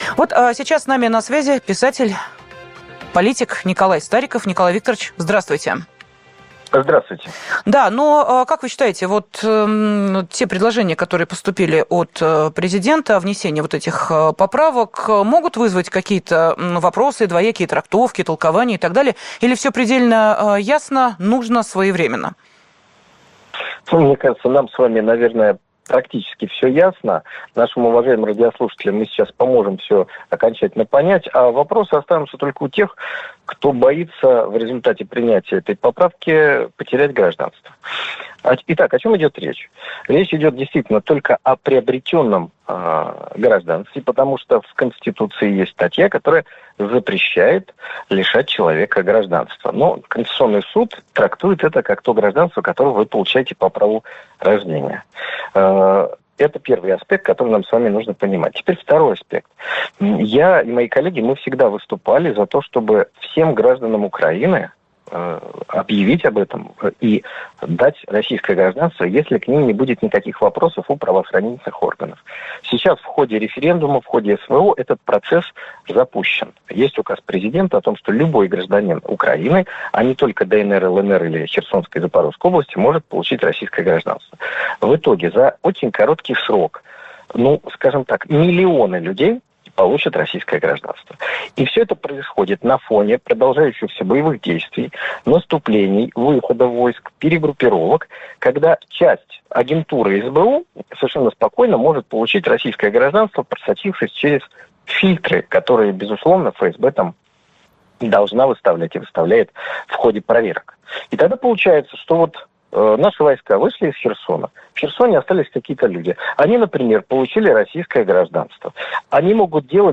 Президентскую поправку, уточняющую и расширяющую список оснований для лишения российского гражданства, наряду с предложениями дать Союзу Журналистов регулировать деятельность блогеров – всё это мы обсудили в прямом эфире радио «Комсомольская Правда».